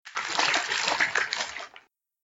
دانلود صدای قایق 2 از ساعد نیوز با لینک مستقیم و کیفیت بالا
جلوه های صوتی